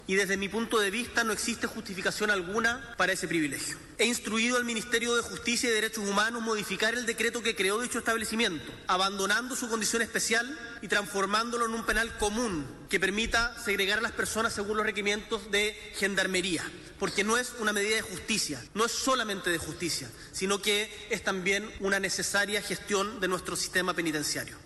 cuna-tl-discurso-boric-punta-peuco.mp3